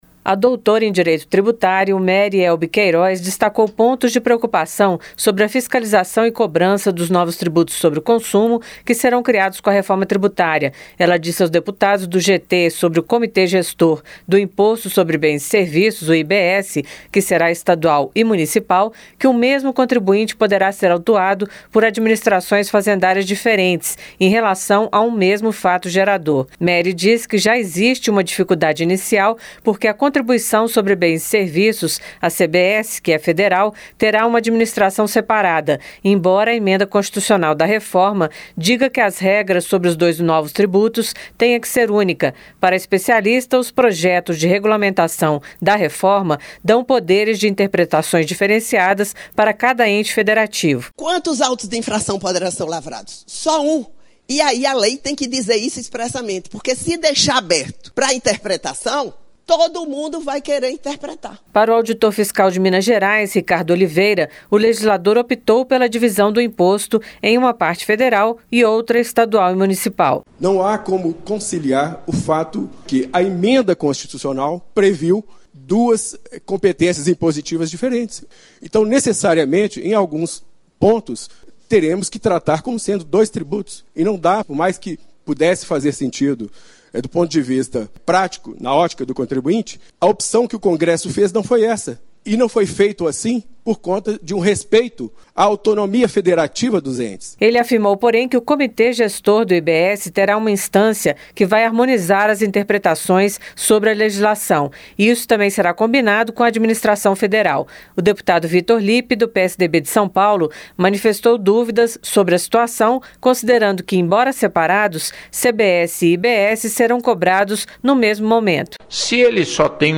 DEBATEDORES DISCUTEM SOBRE A POSSIBILIDADE DE FISCALIZAÇÕES DIFERENCIADAS NOS NOVOS TRIBUTOS SOBRE CONSUMO.